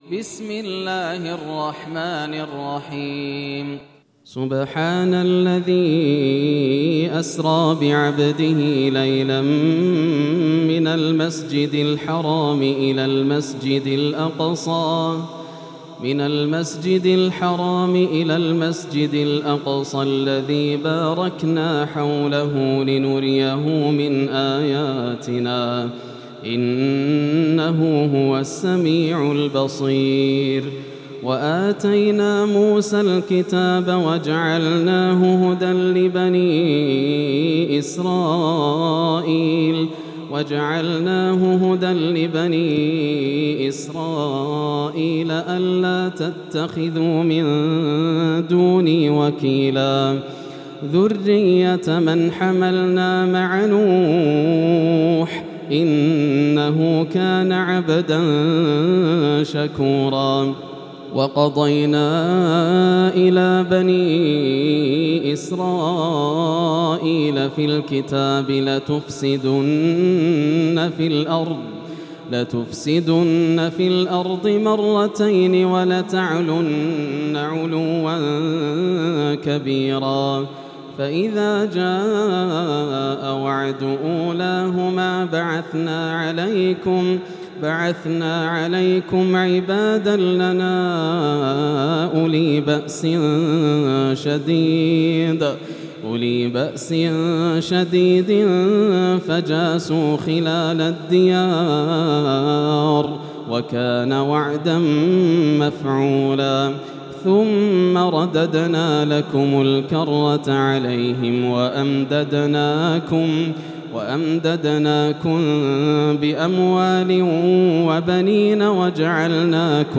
سورة الإسراء > السور المكتملة > رمضان 1433 هـ > التراويح - تلاوات ياسر الدوسري